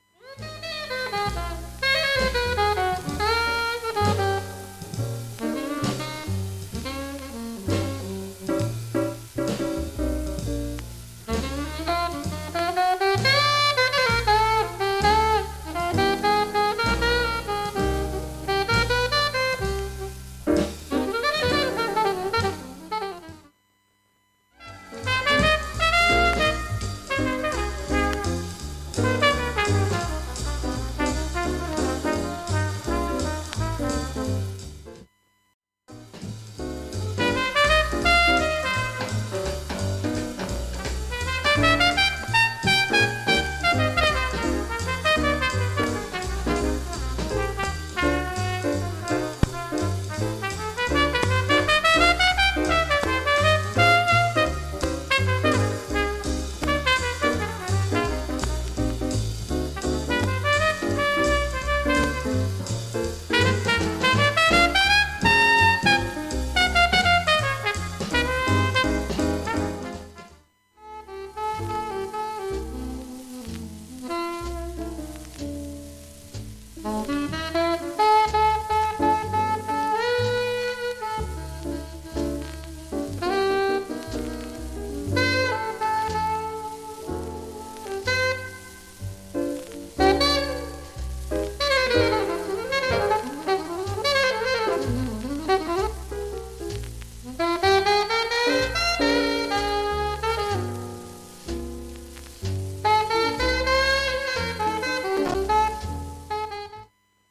B-3全般わずかな周回シャリ音入ります。
サーフェス音は盤面全体に出ています。
問題なし音質良好全曲試聴済み。